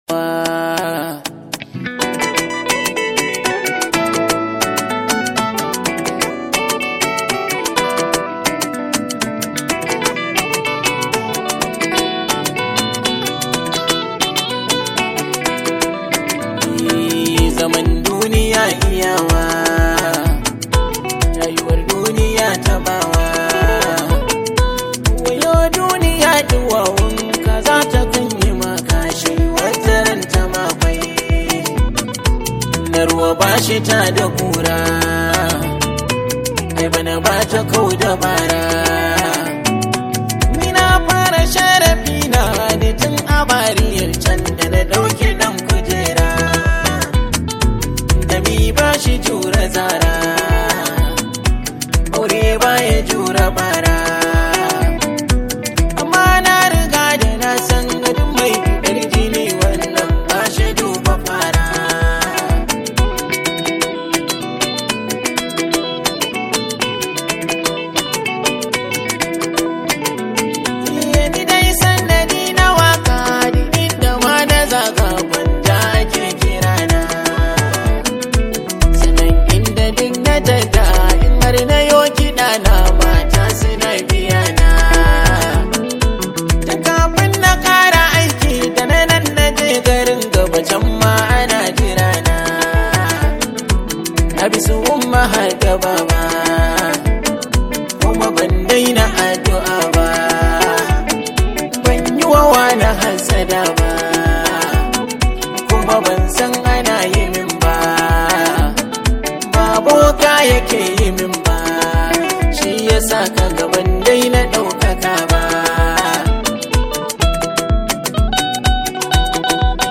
Hausa Songs